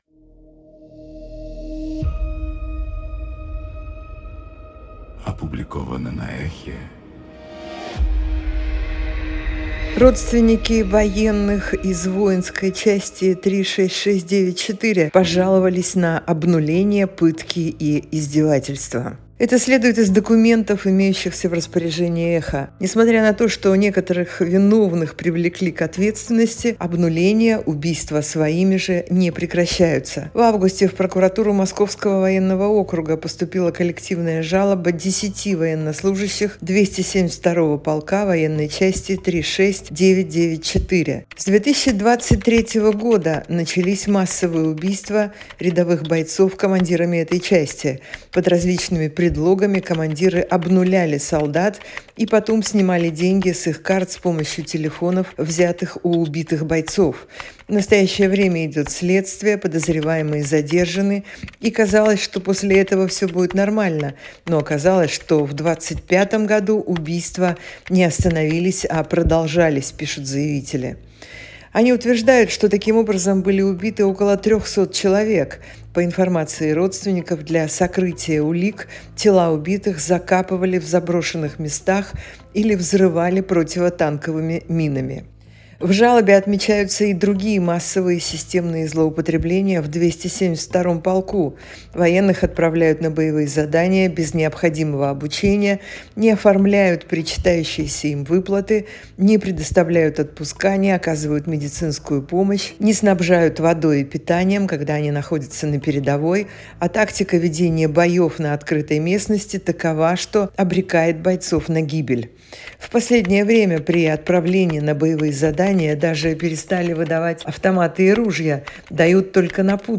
Читает Ольга Бычкова